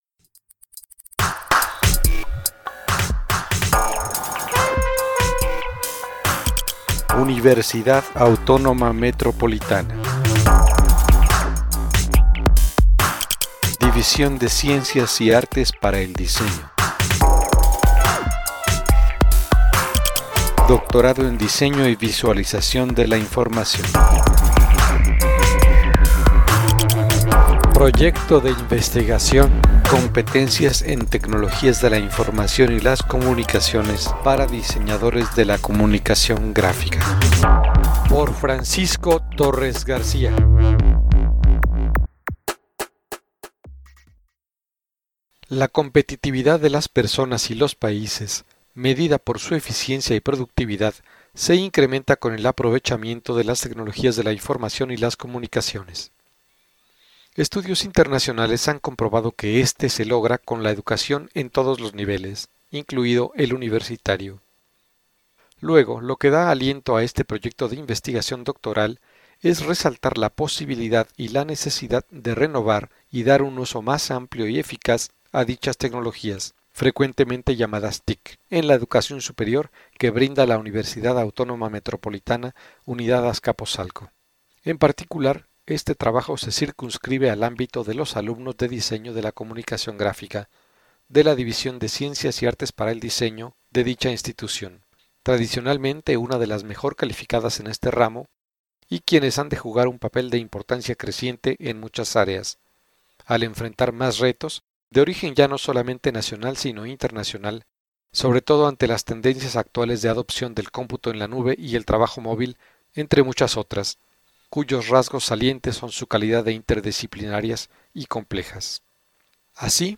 Breve audioresumen (~6′), en forma de podcast, del proyecto doctoral.
Este audio resumen fue grabado en la UAM Azcapotzalco en junio de 2016.